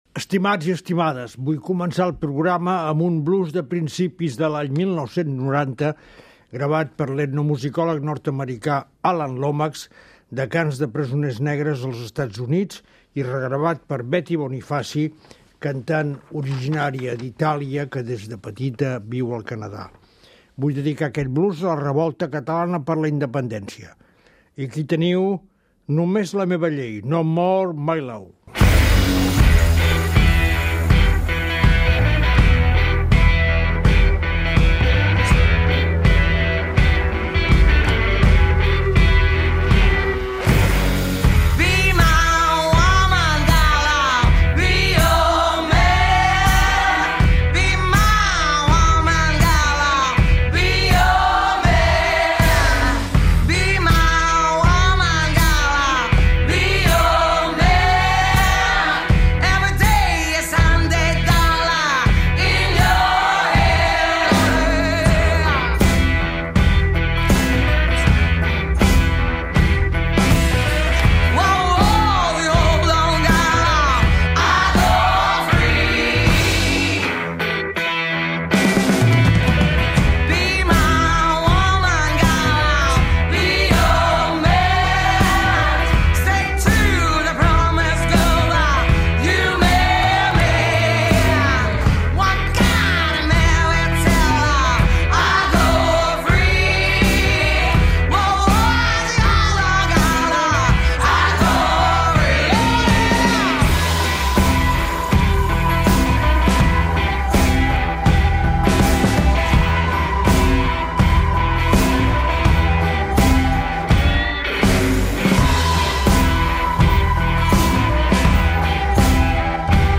Presentem un bluesman rural nord-americà, un pagès parcer.
Farcit de sinceritat i d'una qualitat musical excel·lent.
en directe